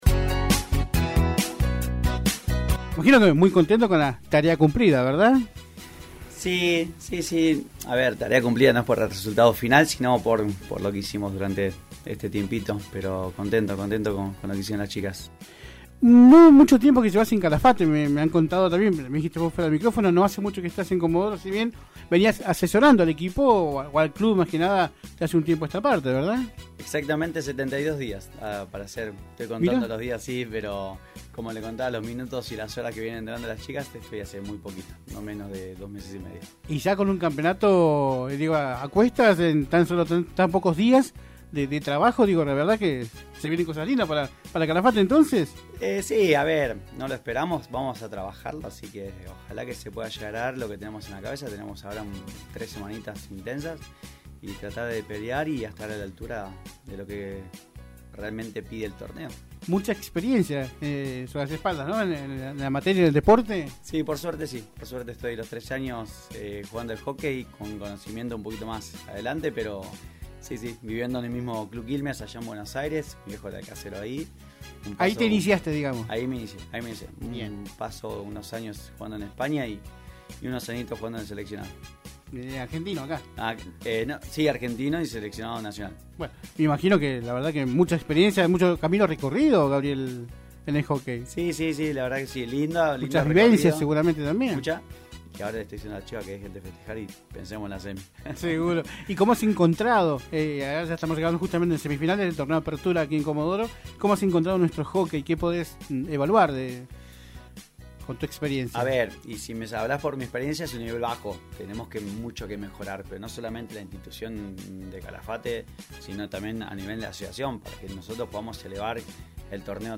En Visión Deportiva nos visitó parte del plantel de 1° Damas de Calafate R.C. que viene de gritar Campeón en el Campeonato Regional de Clubes de Hocķey Césped en Trelew.